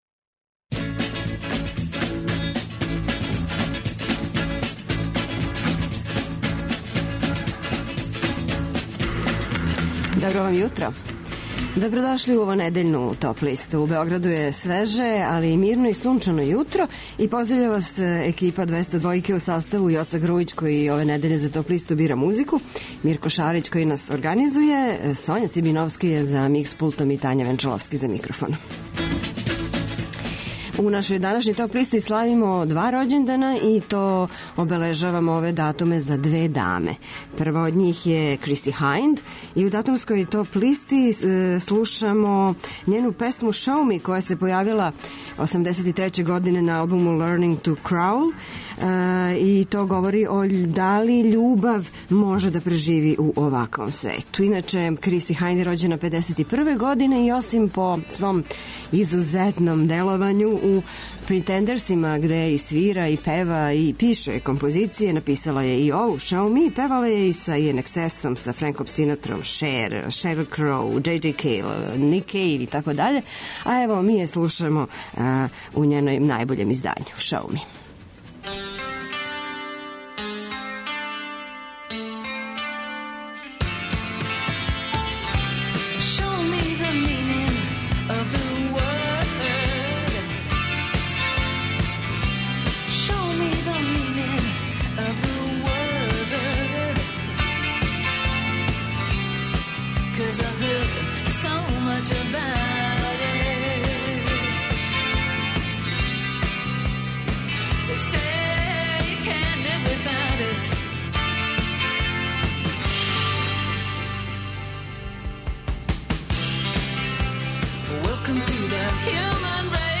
Најавићемо актуелне концерте у овом месецу, подсетићемо се шта се битно десило у историји рок музике у периоду од 07. до 11. септембра. Ту су и неизбежне подлисте лектире, обрада, домаћег и страног рока, филмске и инструменталне музике, попа, етно музике, блуза и џеза, као и класичне музике.